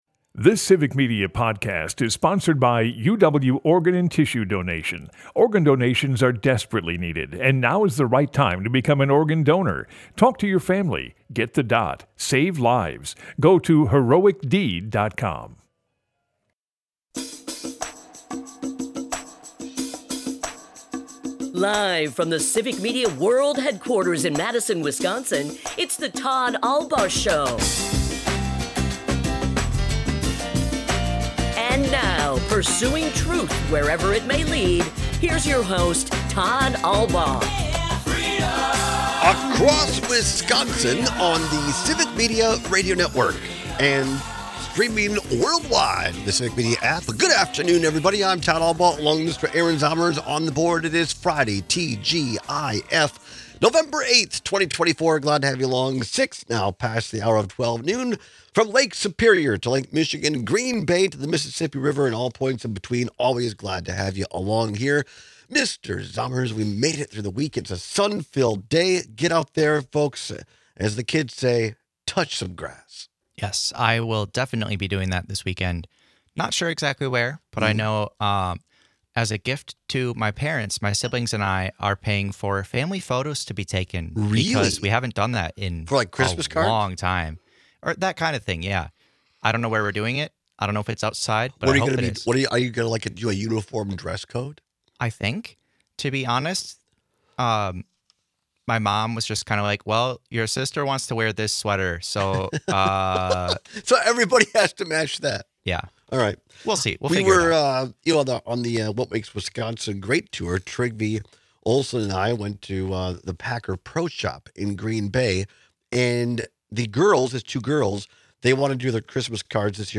Broadcasts live 12 - 2p across Wisconsin.